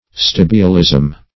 Search Result for " stibialism" : The Collaborative International Dictionary of English v.0.48: Stibialism \Stib"i*al*ism\, n. (Med.) Antimonial intoxication or poisoning.